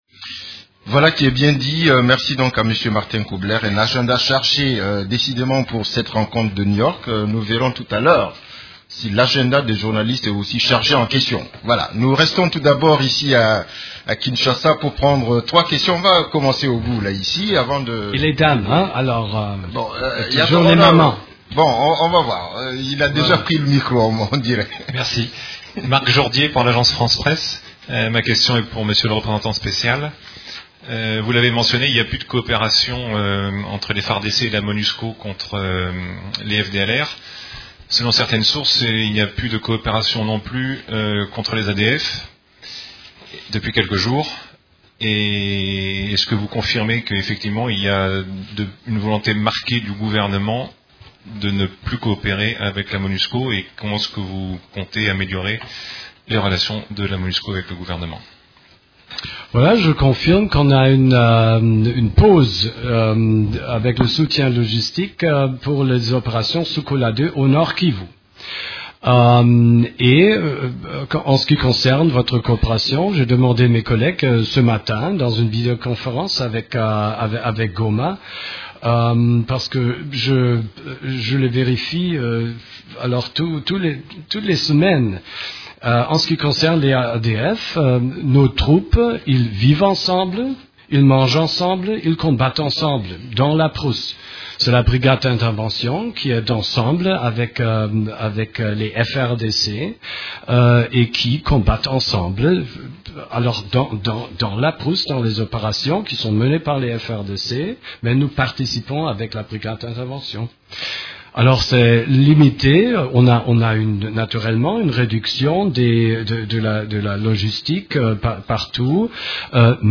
Conférence de presse du 11 mars 2015
La conférence hebdomadaire des Nations unies du mercredi 11 mars à Kinshasa a essentiellement tourné autour des activités des composantes de la Monusco, celles de l’Equipe-pays ainsi de la situation militaire.